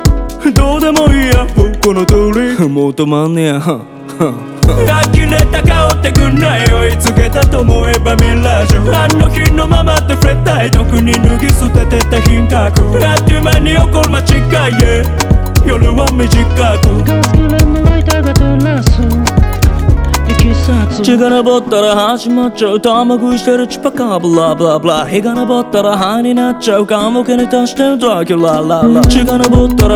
Hip-Hop Rap
Жанр: Хип-Хоп / Рэп